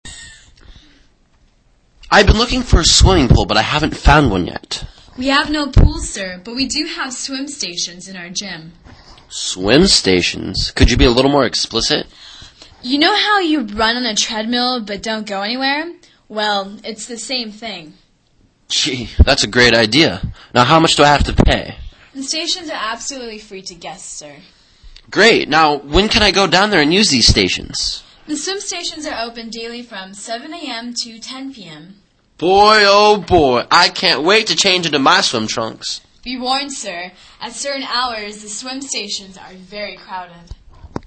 旅馆英语对话-Swimming Pool(5) 听力文件下载—在线英语听力室